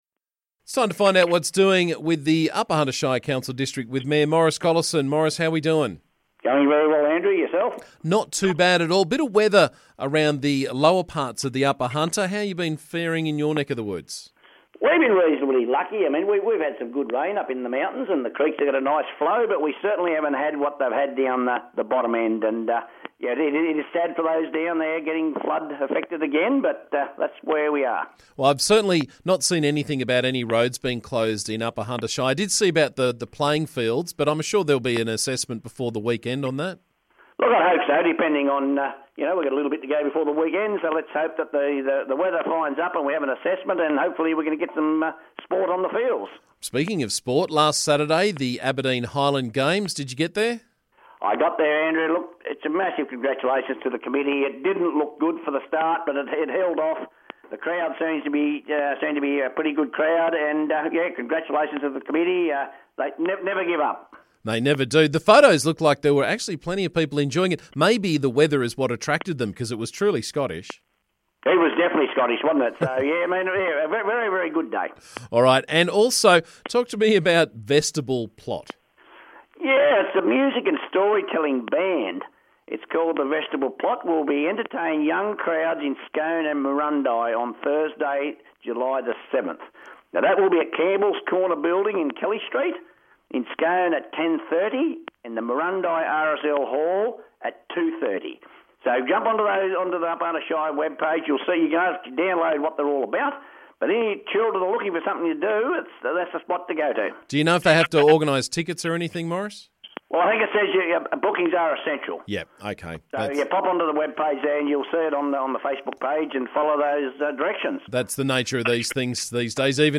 UHSC Mayor Maurice Collison was on the show this morning to keep us up to date with what's doing around the district.